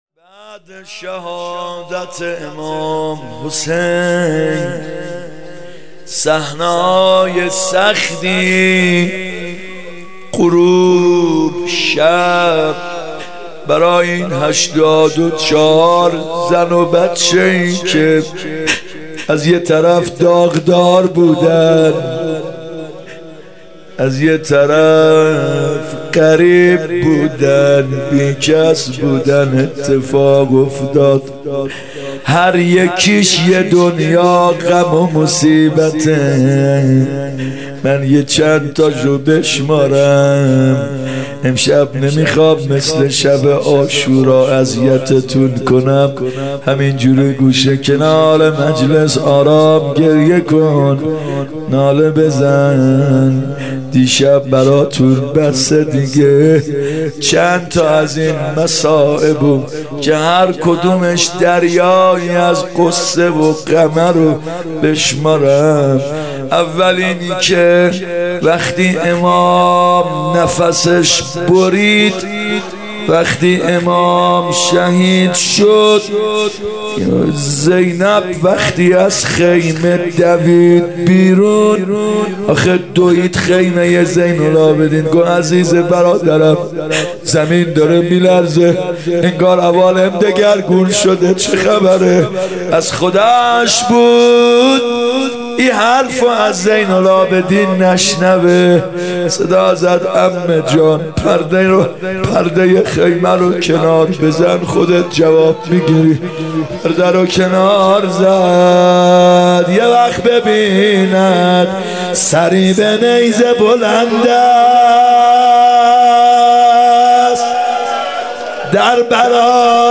محرم 95 شب یازدهم روضه
محرم 95(هیات یا مهدی عج)